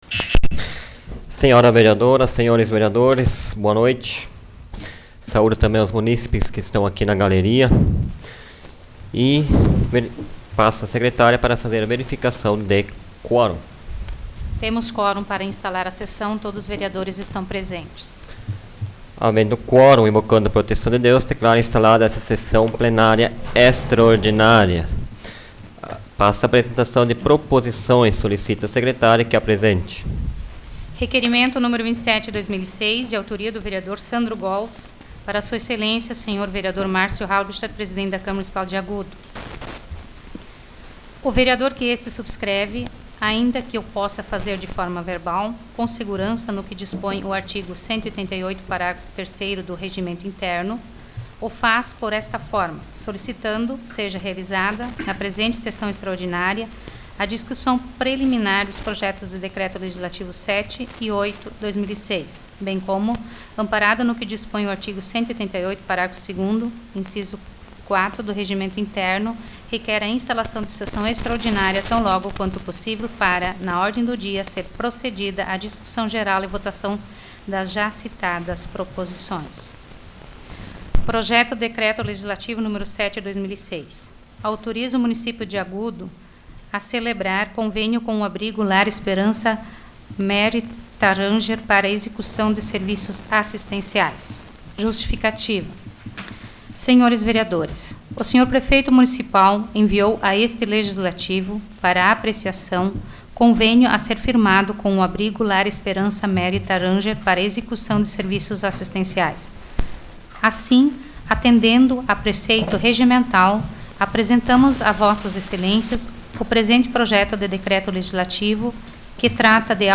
Áudio da 29ª Sessão Plenária Extraordinária da 12ª Legislatura, de 26 de dezembro de 2006